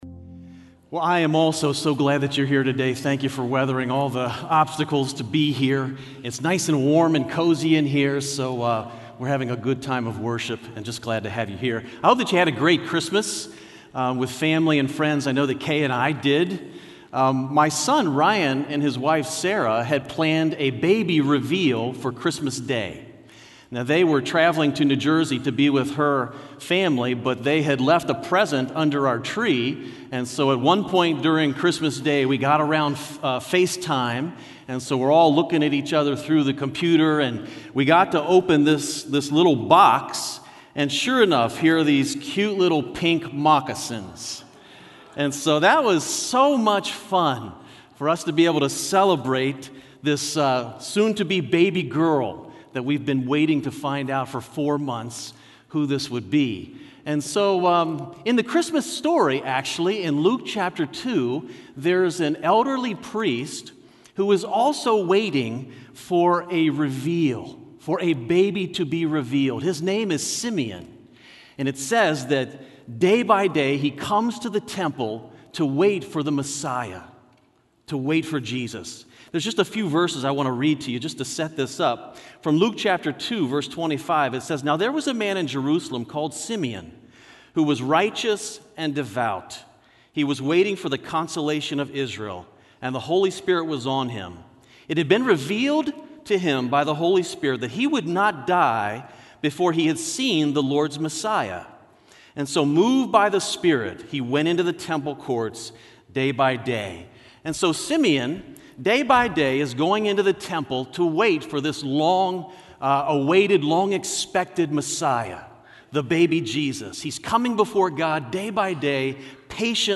Sermon Series